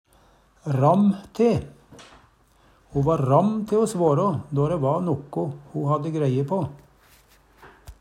ramm - Numedalsmål (en-US)